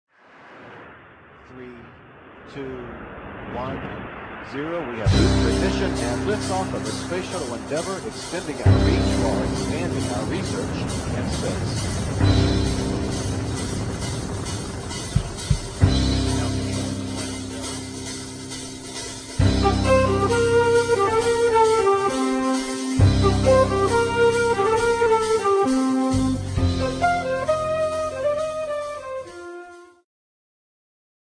ジャンル Jazz
Progressive
癒し系
二胡